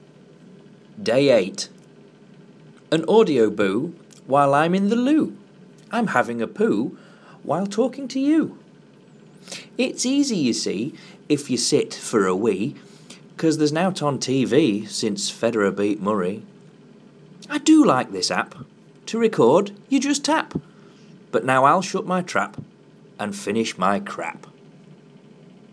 A little ditty.